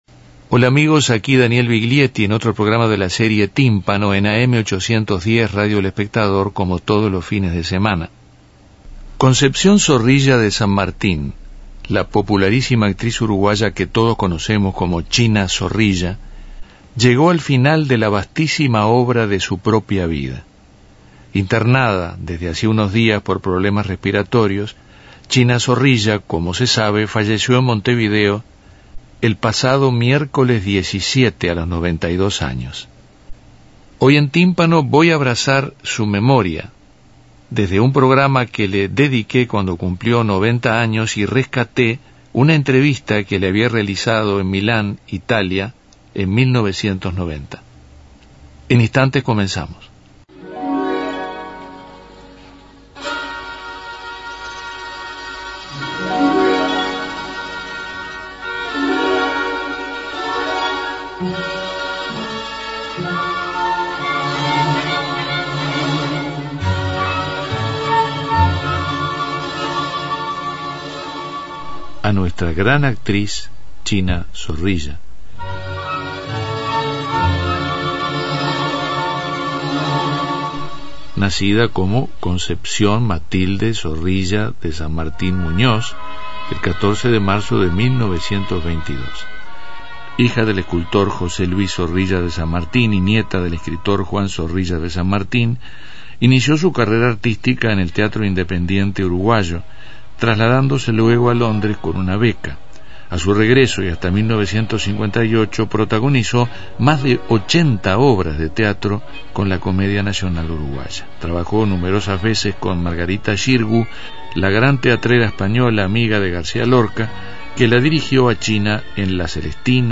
En un abrazo a la memoria de la actriz Concepción "China" Zorrilla, Viglietti retoma la entrevista que le hizo en Milán, Italia, en 1990, durante la Copa del Mundo.